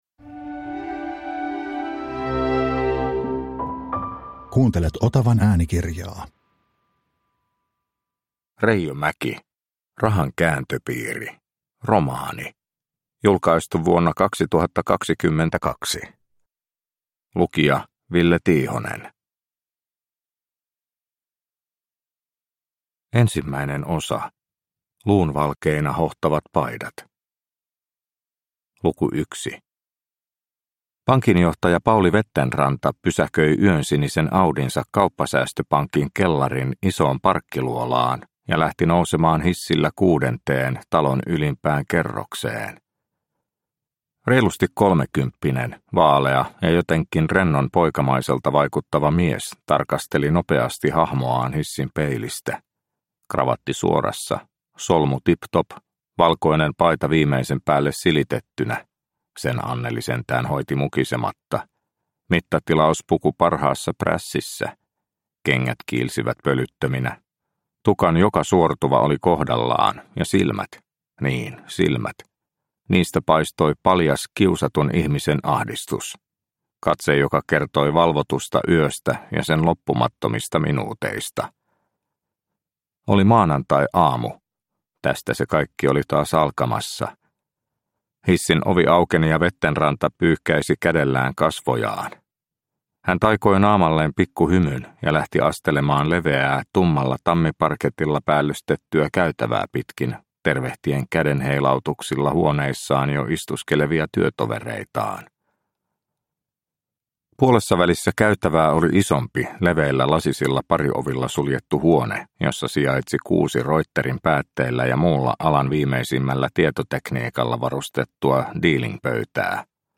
Rahan kääntöpiiri – Ljudbok – Laddas ner